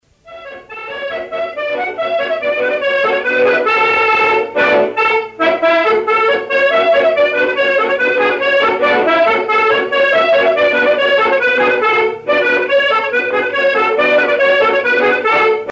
danse : branle : avant-deux
Pièce musicale éditée